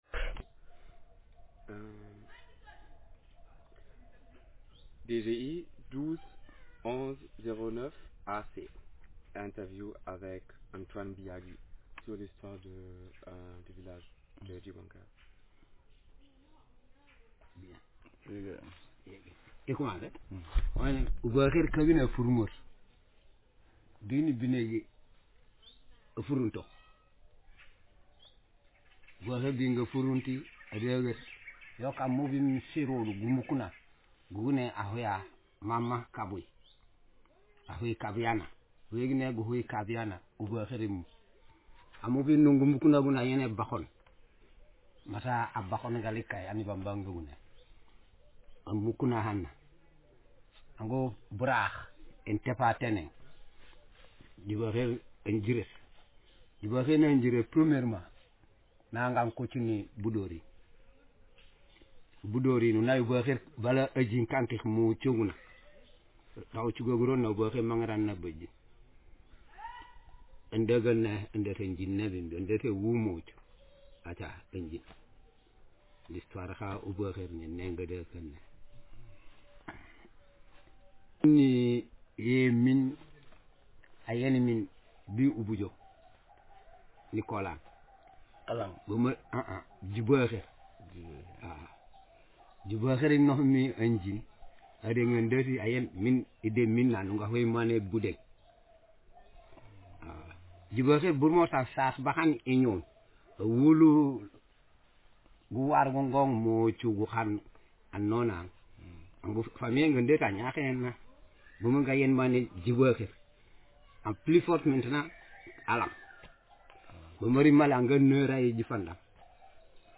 Speaker sex m/m Text genre conversation